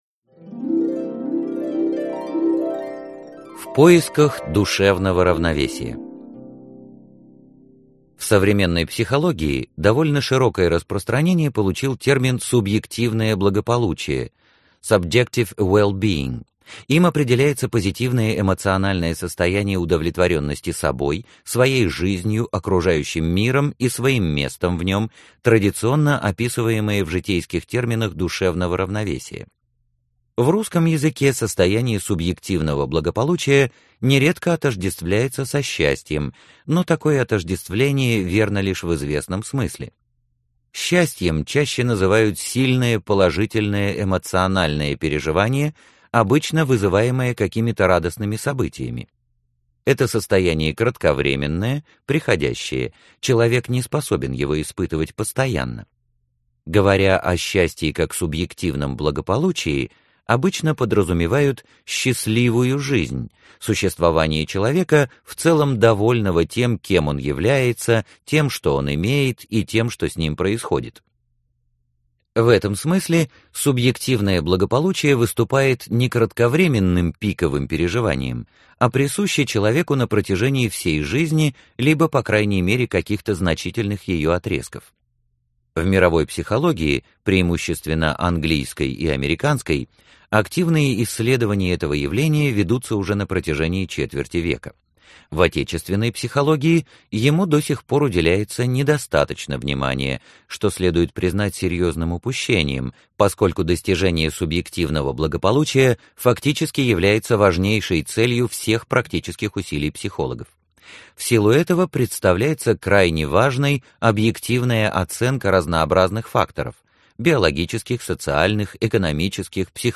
Аудиокнига Психологические шпаргалки | Библиотека аудиокниг